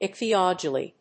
音節ich・thy・ol・o・gy 発音記号・読み方
/ìkθiάlədʒi(米国英語)/